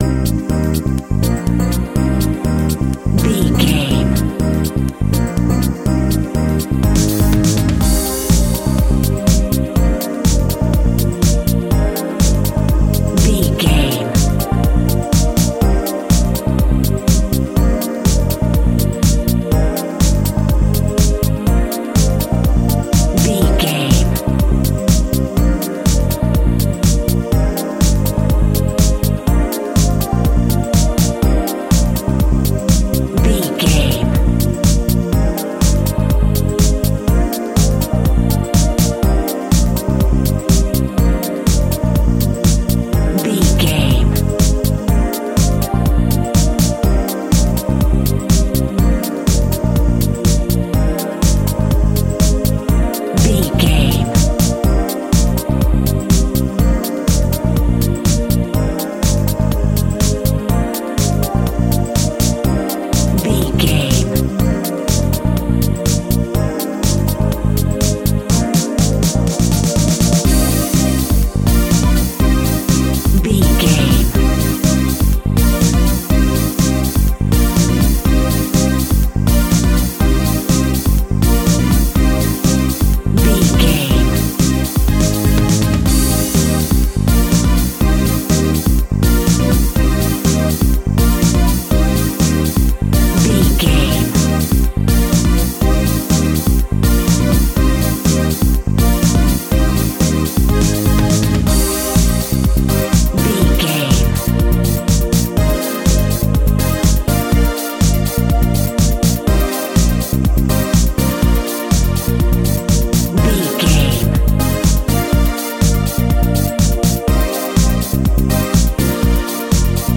Aeolian/Minor
driving
energetic
uplifting
futuristic
hypnotic
industrial
frantic
synthesiser
drum machine
electronica
synth leads
techno music
synth bass
synth pad
robotic